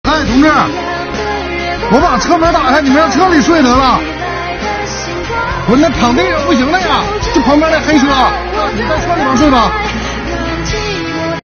楼上的大哥看在眼里
心疼地朝楼下喊话：